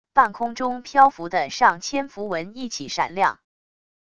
半空中漂浮的上千符文一起闪亮wav音频